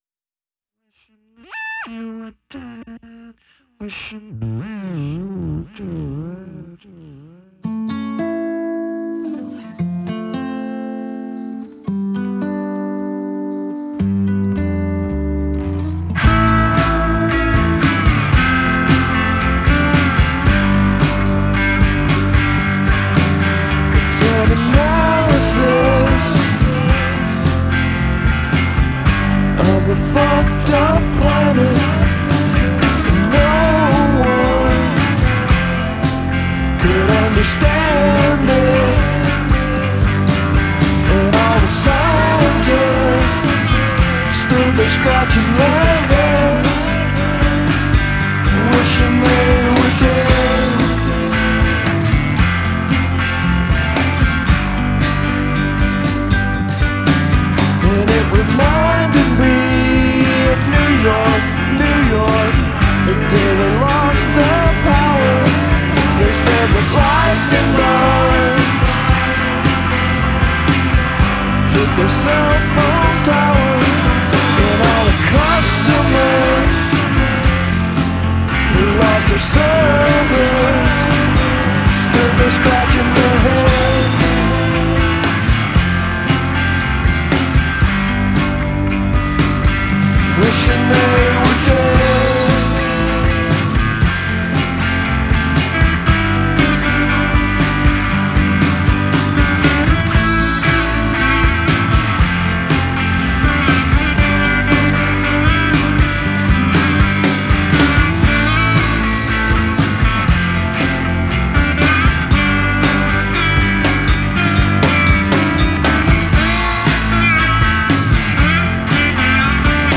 classic-rocker